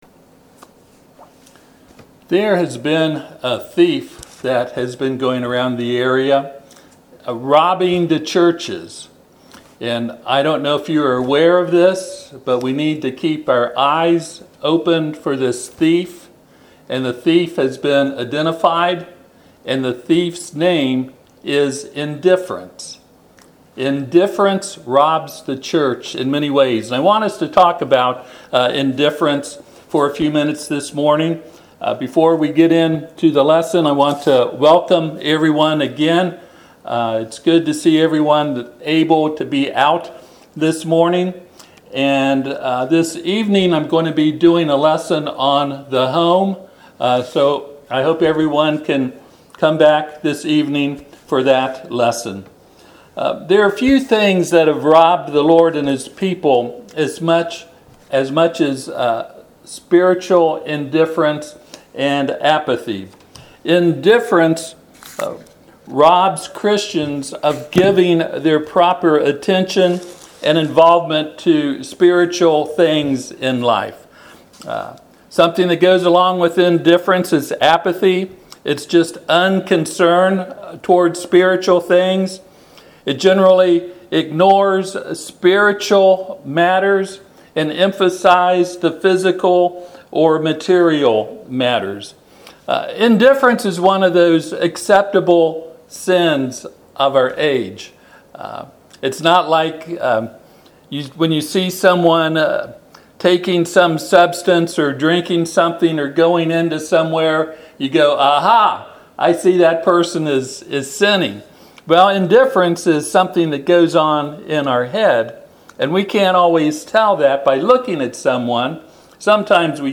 Revelation 3:15-16 Service Type: Sunday AM Revelation 3:15 “I know your works